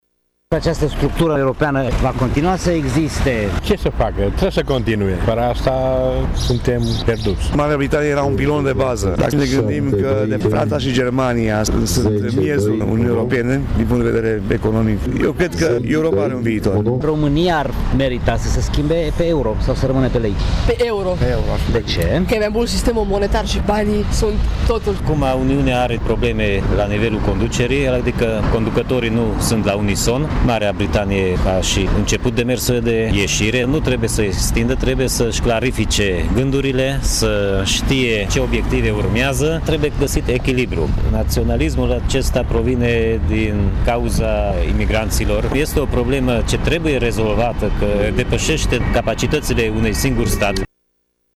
Târgumureșenii privesc cu optimism viitorul Uniunii Europene, dar îi îngrijorează curentele naționaliste care apar în țările occidentale și problema migrației: